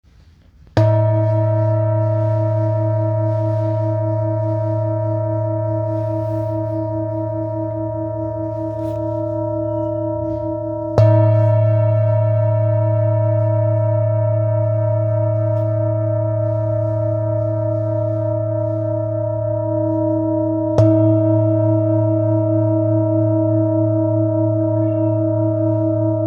Singing Bowl, Buddhist Hand Beaten, with Fine Etching Carving of Mandala, Sri Yantra, Select Accessories
Material Seven Bronze Metal